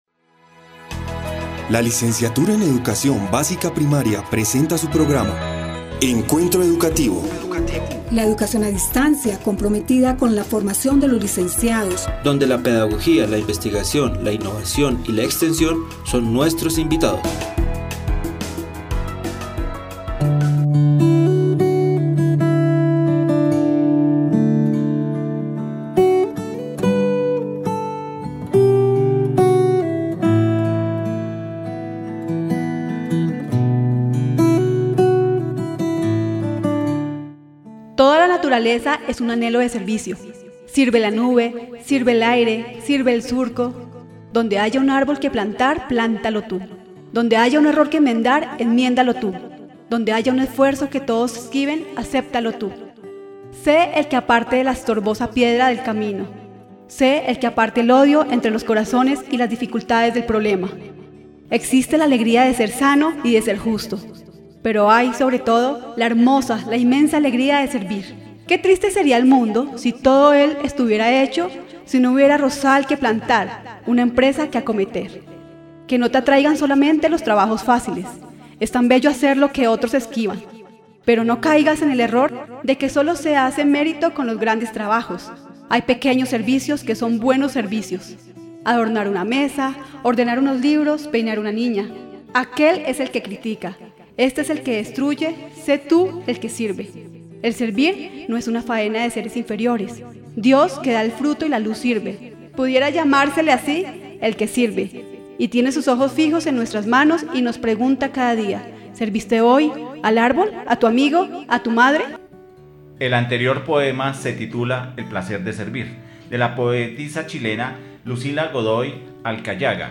Programa de radio: "Proyección social"